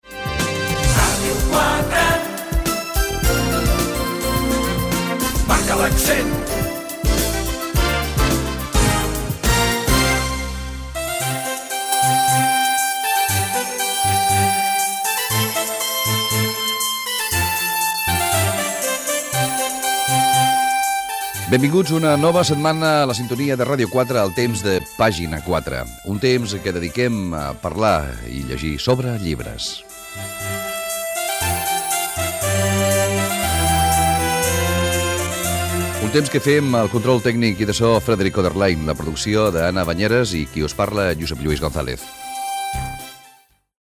Indicatiu de la ràdio, presentació del programa i equip